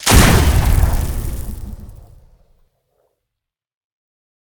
pistol1.ogg